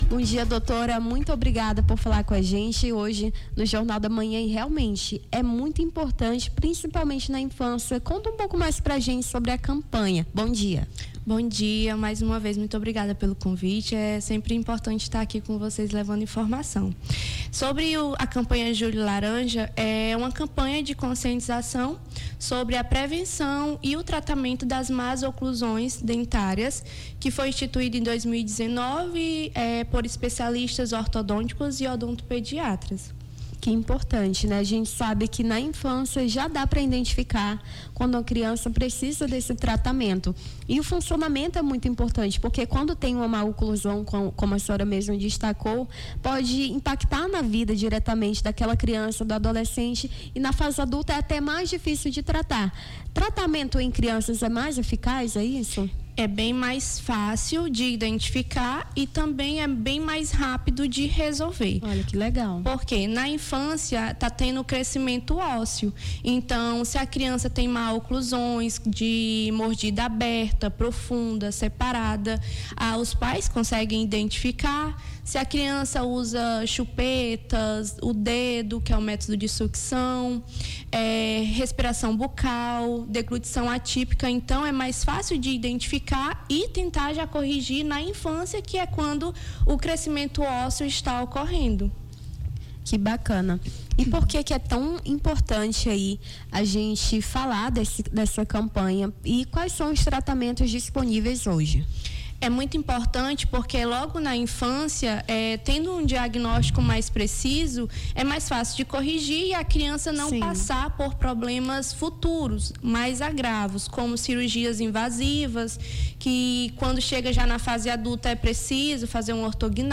a apresentadora
a dentista
Nome do Artista - CENSURA - ENTREVISTA (JULHO LARANJA) 07-07-25.mp3